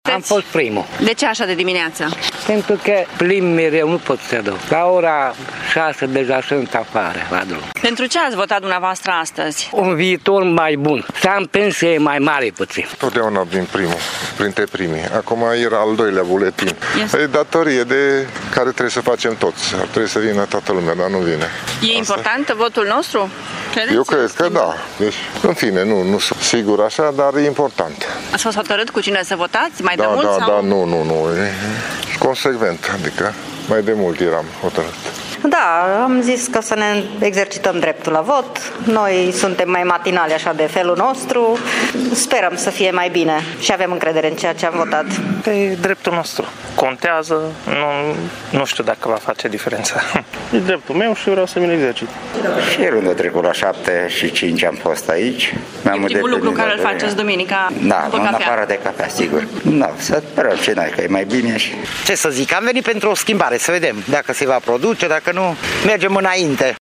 Cei mai matinali dintre târgumureșeni spun că, de regulă, vin primii la vot pentru a-și face datoria cetățenească și speră ca de acum să fie mai bine: